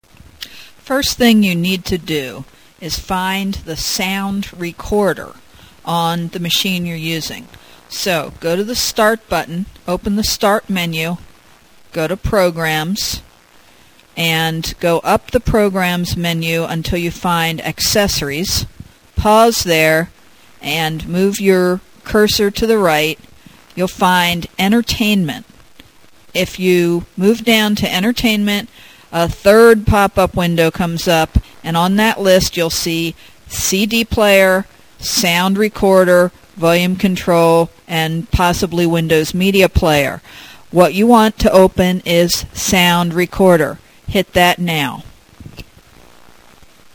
For voice quality equivalent to what you hear on these pages, you can record at 11.025 KHz 8-bit mono.